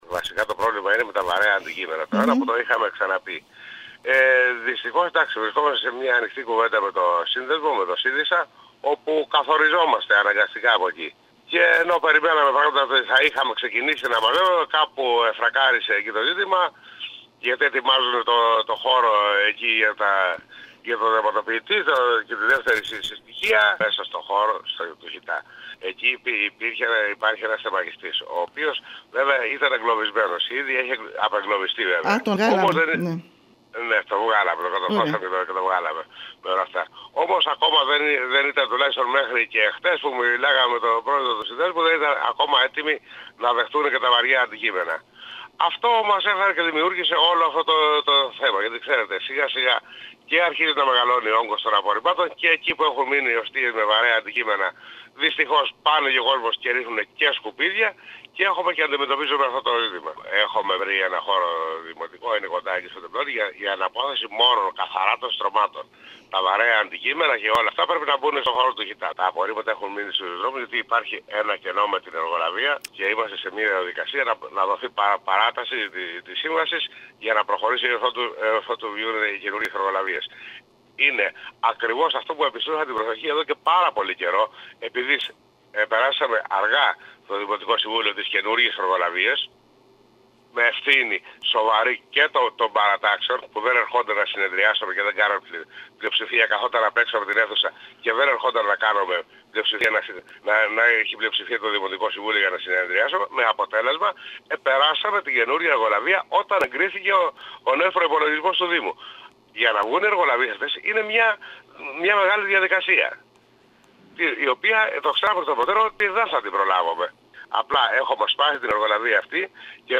Συνεχίζονται τα προβλήματα στην αποκομιδή των βαρέων αντικειμένων αλλά και των απορριμμάτων σε πολλές περιοχές της Κέρκυρας. Μιλώντας στην ΕΡΤ Κέρκυρας ο αντιδήμαρχος καθαριότητας επεσήμανε ότι έχει λήξει η σύμβαση του εργολάβου που είχε αναλάβει την αποκομιδή σε περιοχές του βορείου συγκροτήματος αλλά και της μέσης Κέρκυρας και η υπηρεσία προσπαθεί με τις δικές της δυνάμεις να αντεπεξέλθει ενώ γίνονται προσπάθειες έτσι ώστε τις επόμενες μέρες να προχωρήσει ο δήμος στην παράταση της σύμβασης του εργολάβου.